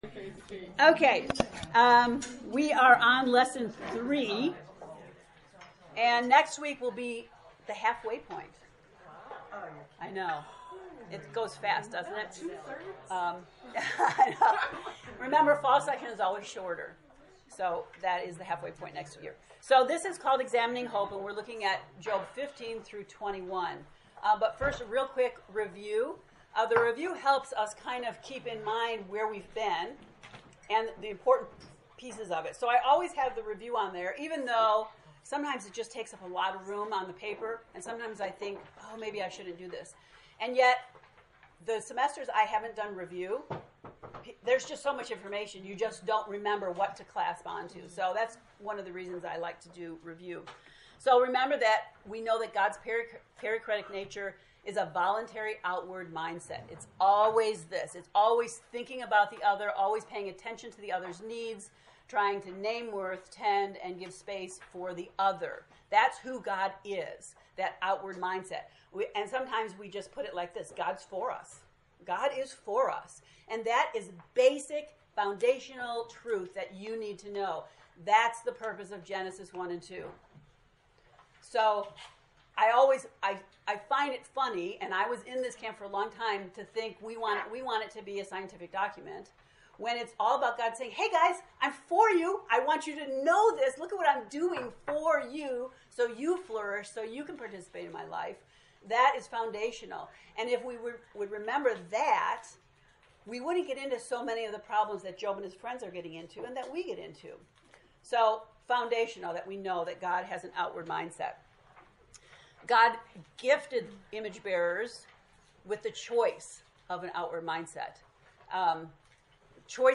To listen to the Lesson 3 lecture, “Examining Hope,” click below: